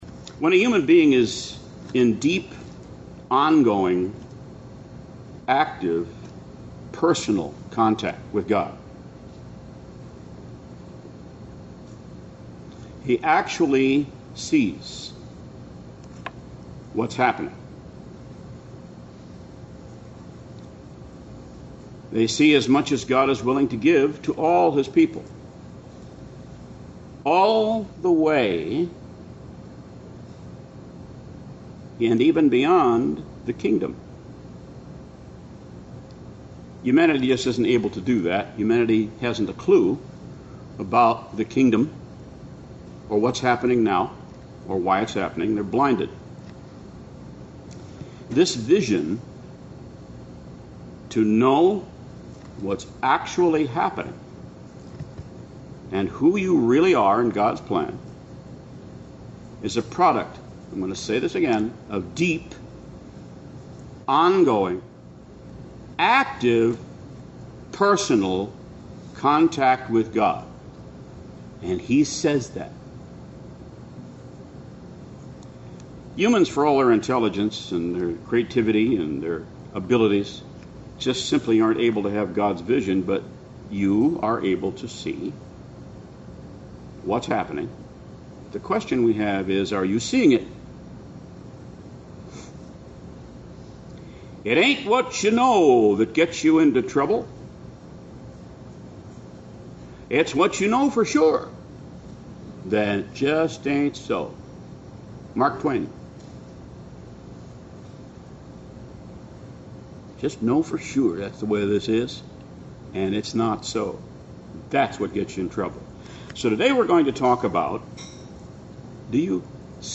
Sermons
Given in Beloit, WI